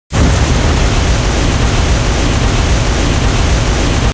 Rocket Booster Fire Loop
Rocket Booster Fire Loop is a free nature sound effect available for download in MP3 format.
Rocket Booster Fire Loop.mp3